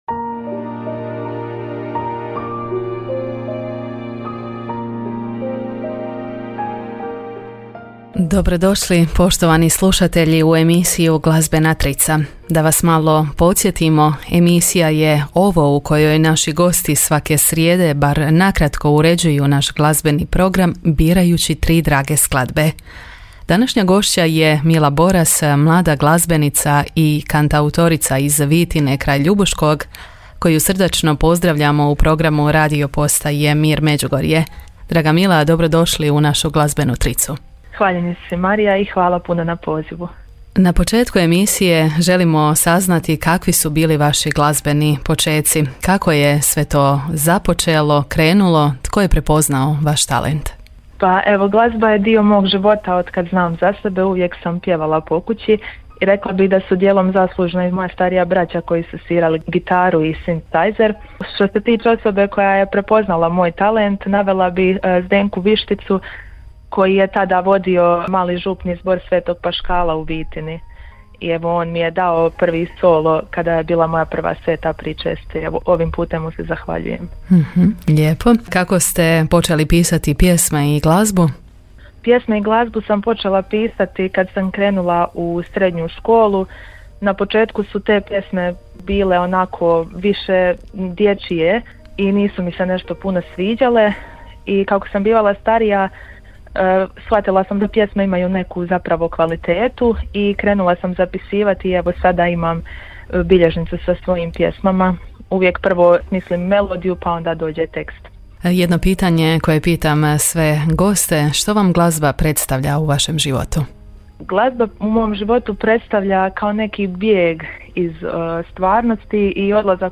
a cijeli razgovor i tri pjesme koje je odabrala možete čuti u audiozapisu.